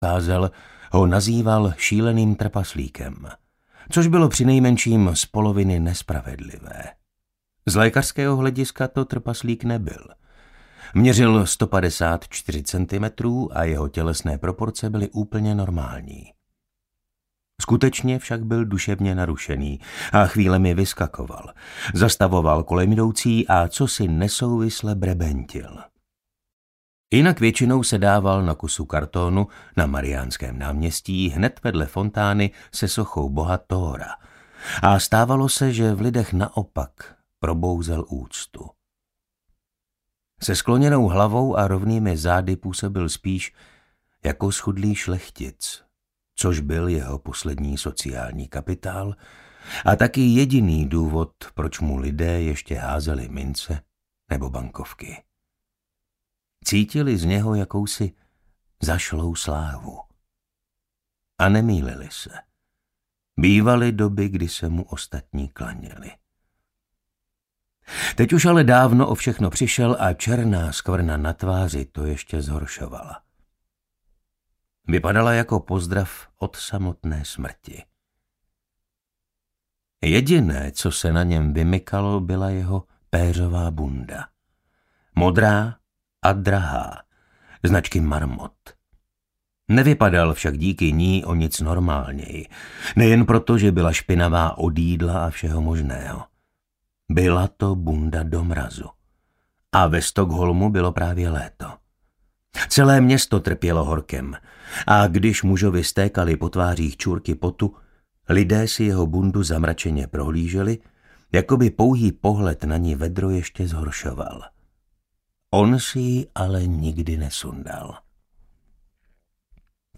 3x Salanderová & Blomkvist #2 audiokniha
Ukázka z knihy